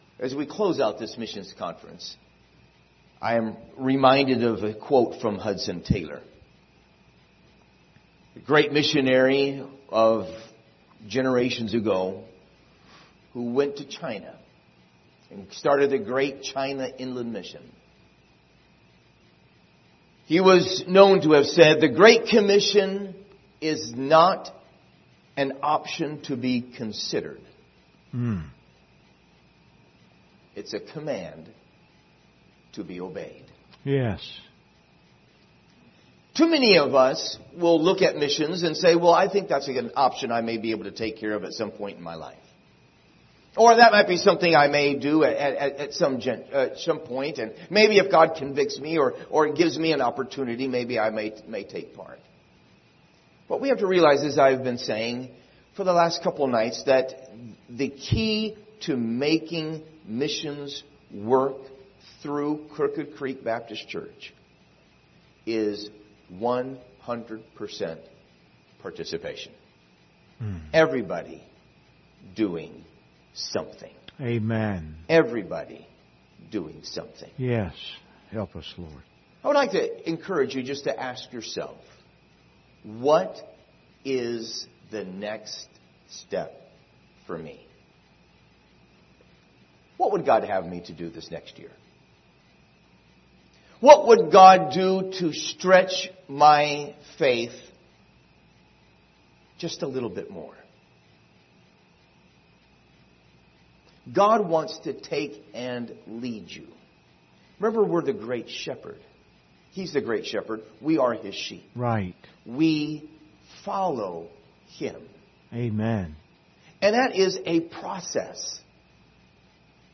Speaker: Missionary
Series: 2025 Missions Conference Passage: 2 Corinthians 4:3-4, Acts 26:18 Service Type: Special Service